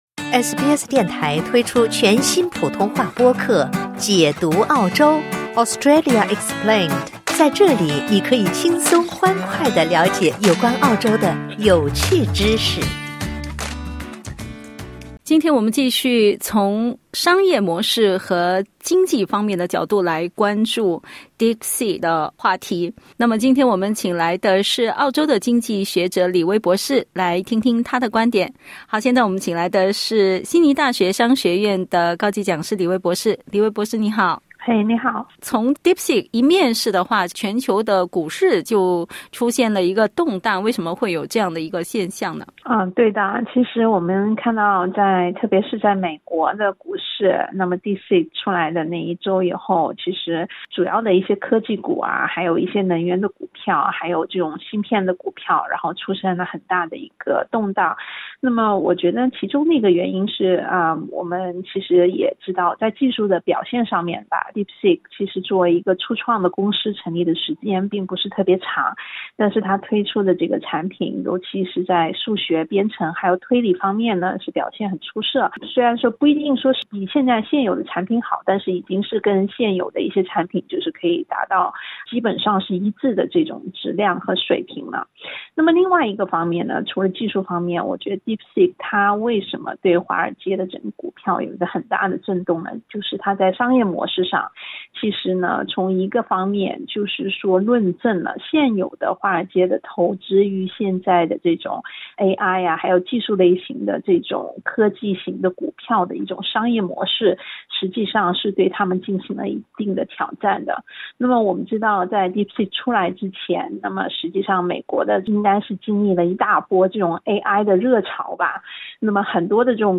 （点击音频收听详细采访） DeepSeek开发的新型人工智能聊天机器人的问世一面世就引起了全球股市的动荡，并刺激了美国和中国之间的全球和经济竞争。